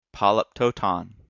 /ˌpɑl.əpˈtoʊ.tɑn(米国英語)/